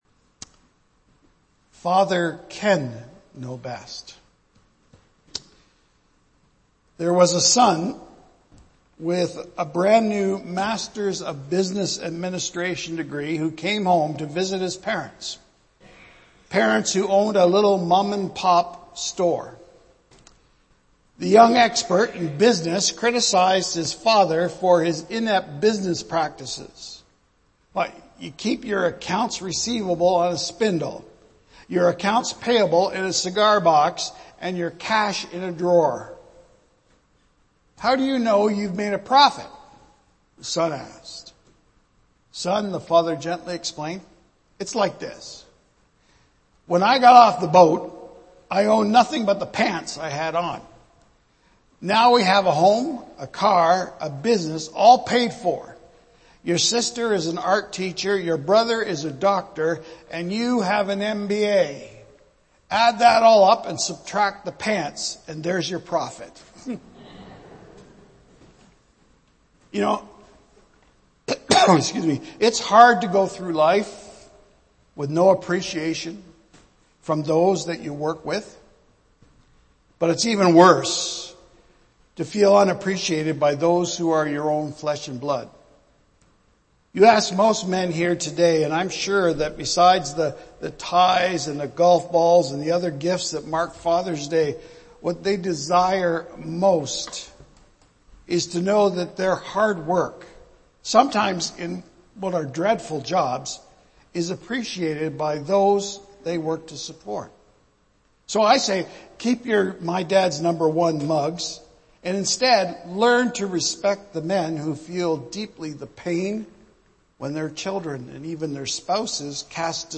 First Baptist Church of Simcoe
Archived Sermons